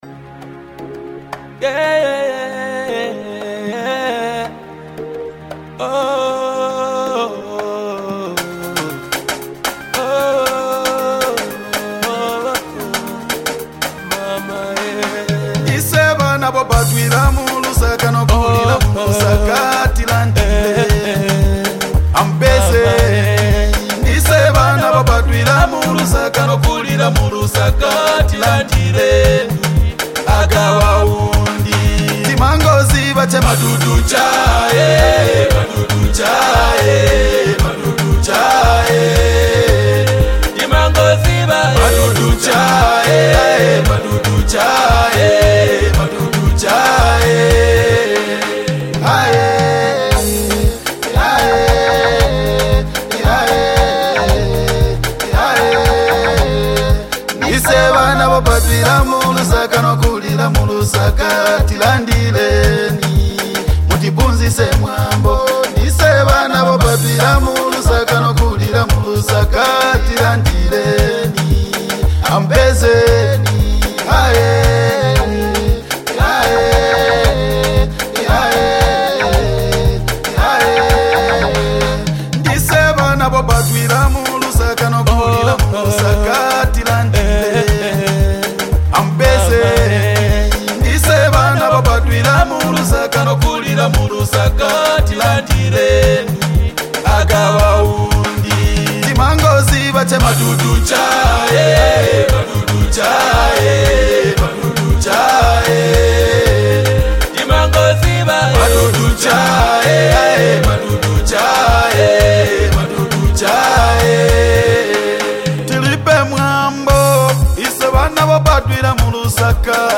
catchy tune